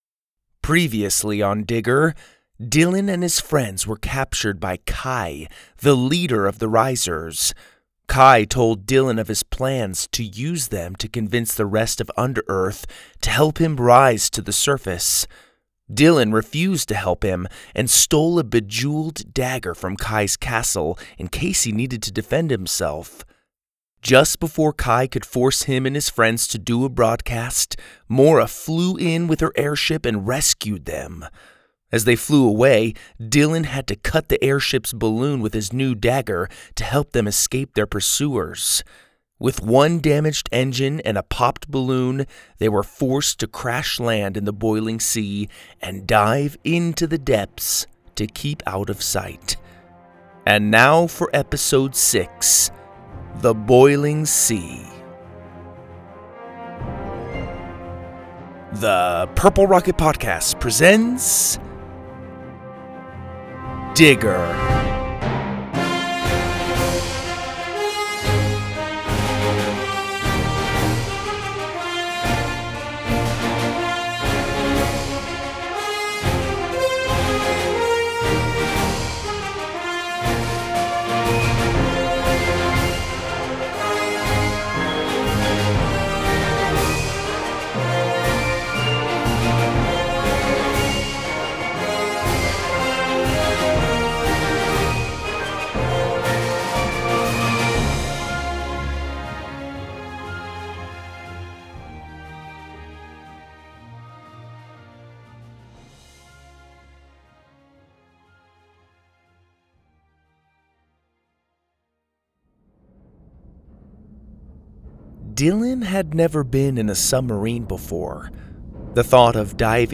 Stories For Kids, Kids & Family